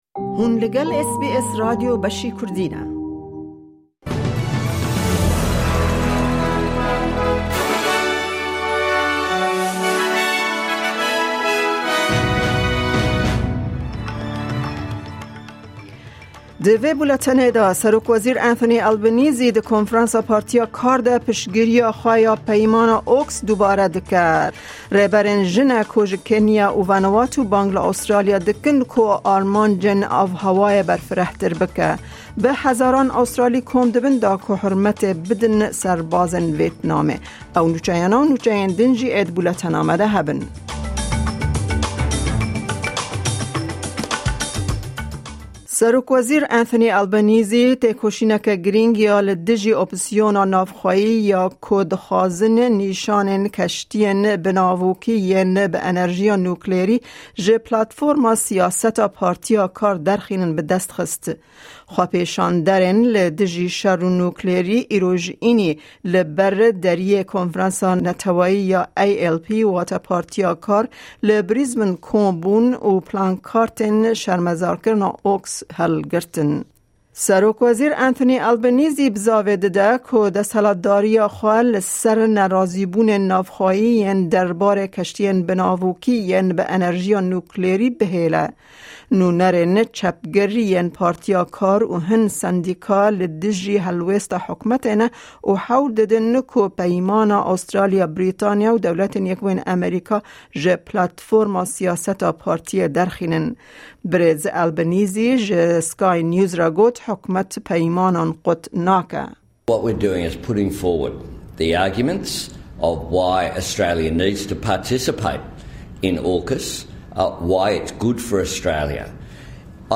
Kurdish News